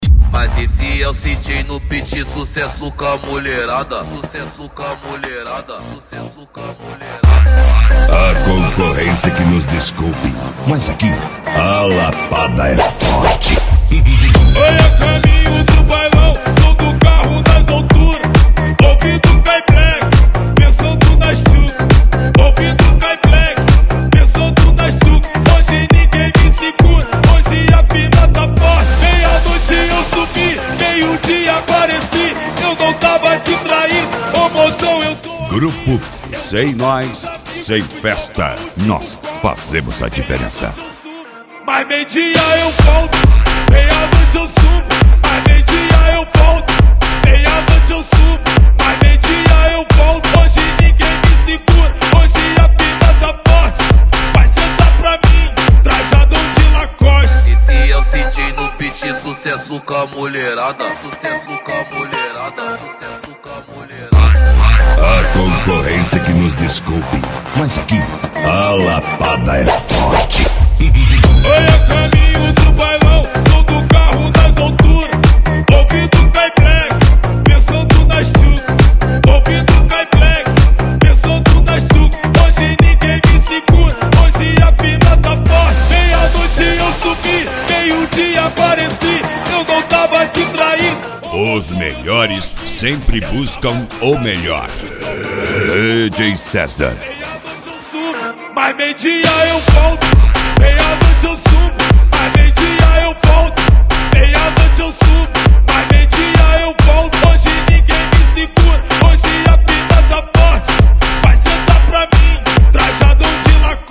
Eletronica
Funk
Funk Nejo
Mega Funk